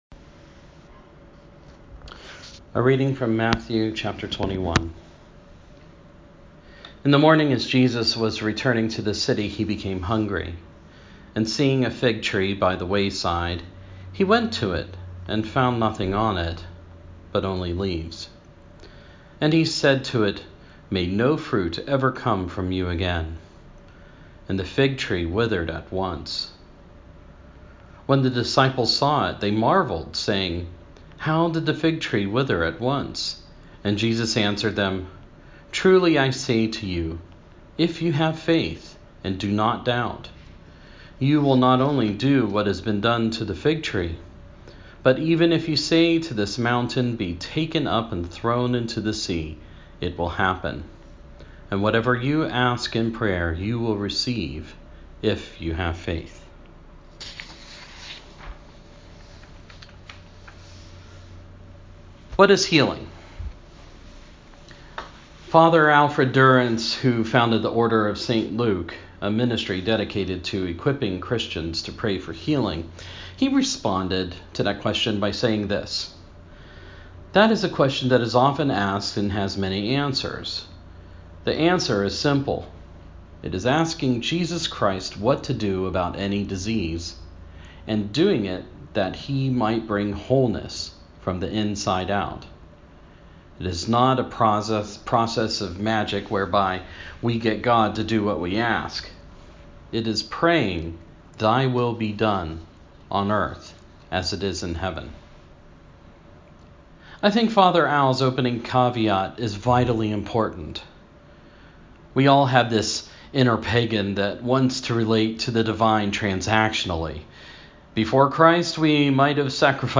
Reflecting on Matthew 21:18-22, we considered what healing is. I forgot to record it at the time so please read the Scripture, listen to the post-recorded reflection, and offer the prayer for gifts of healing below.